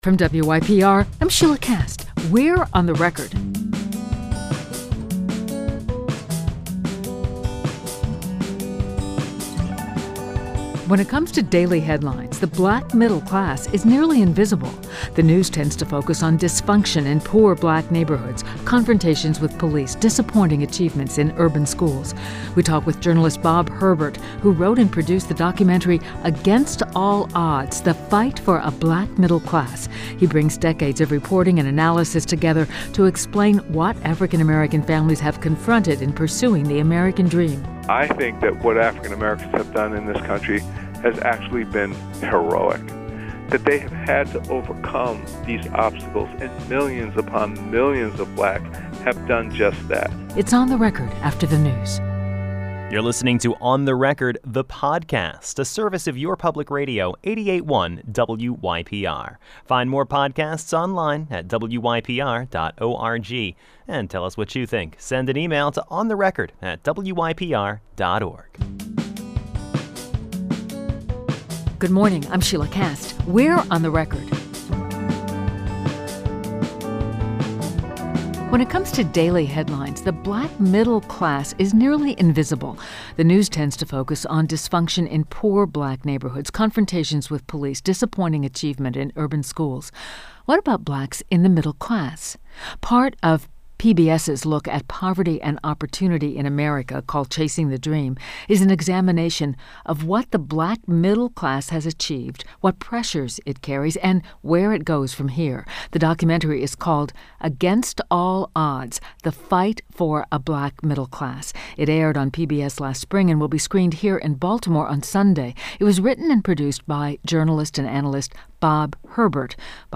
An Interview with Journalist Bob Herbert on the Black Middle Class – Against All Odds